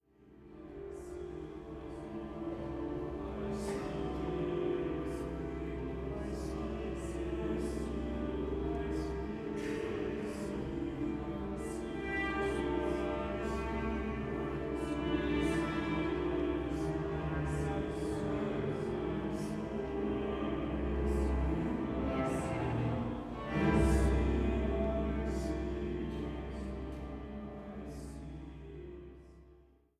in Mitschnitten der Uraufführungen
Motette